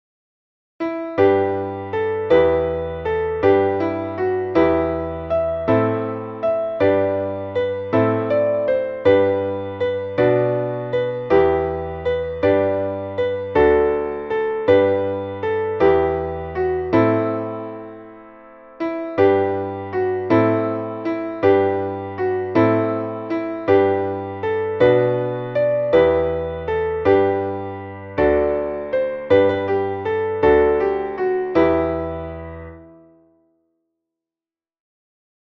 Traditionelles Neujahrslied